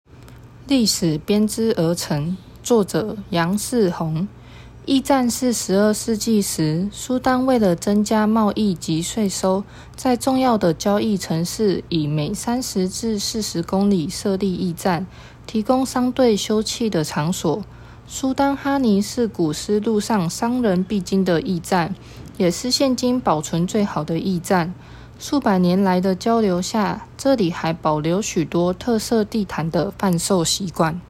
語音導覽，另開新視窗